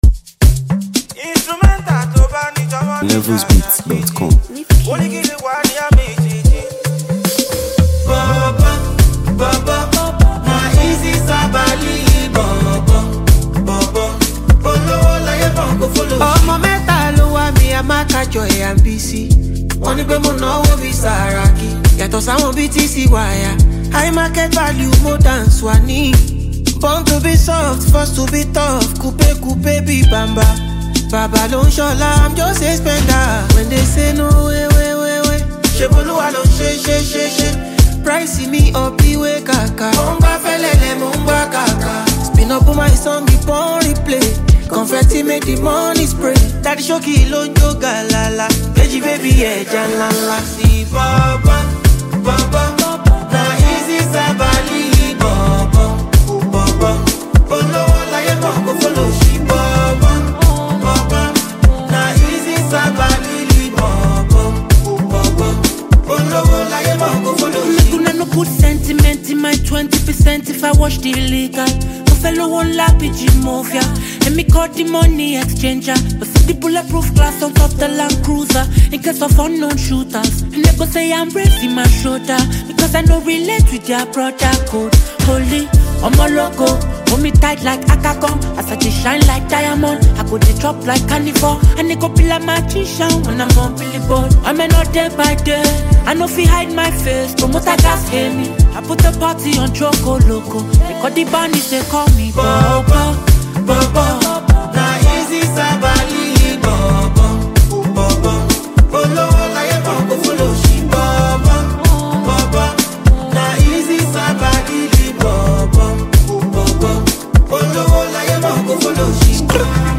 a sensational Nigerian singer known for his smooth vocals
blends infectious rhythms with feel-good melodies